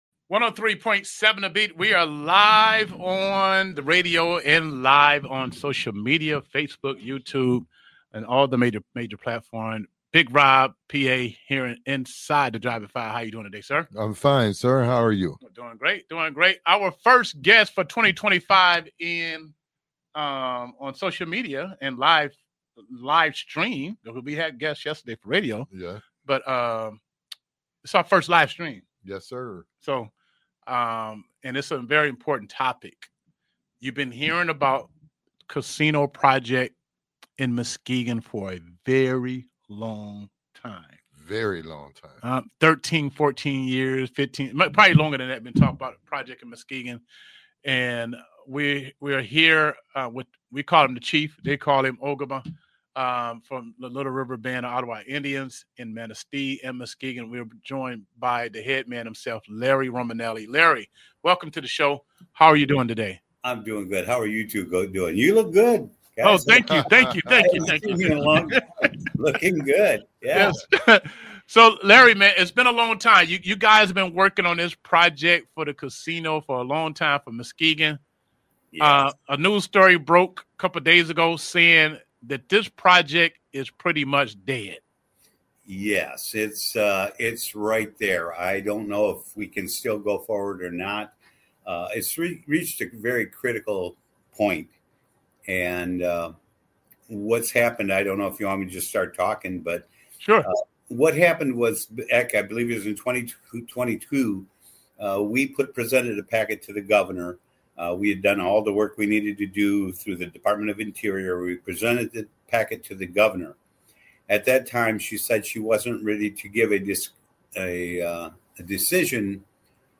Interview with Larry Romanelli regarding Muskegon Casino